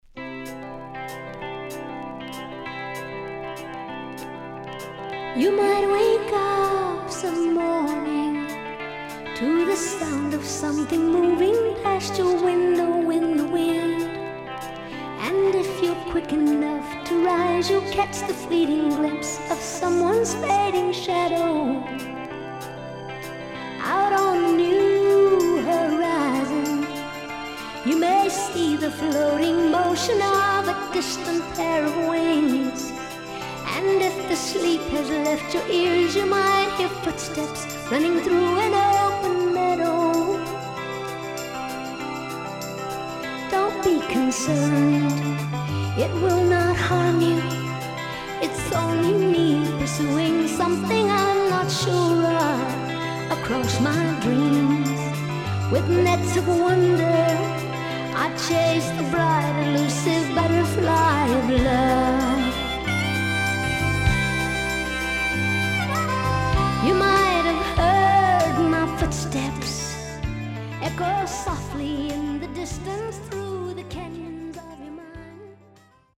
CONDITION SIDE A:VG(OK)
SIDE A:全体的にチリノイズ入ります。